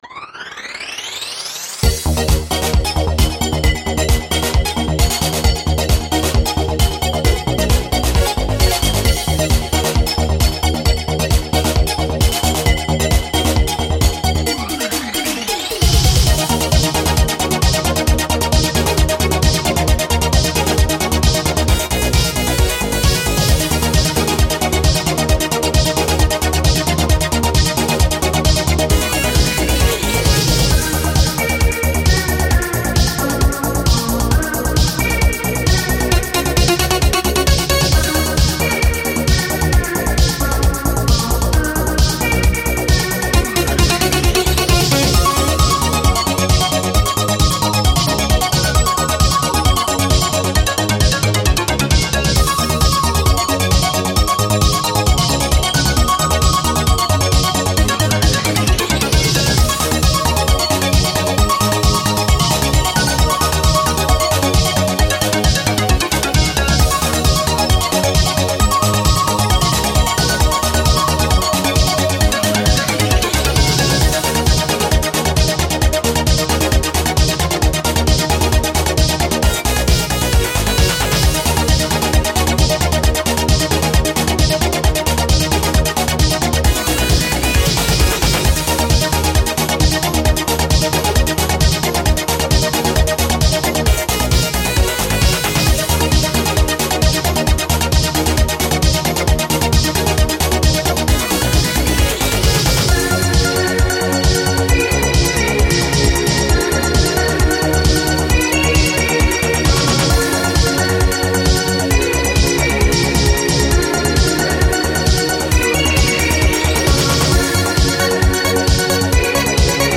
Genre: House , Trance , Spacesynth , Synthpop , Electronic.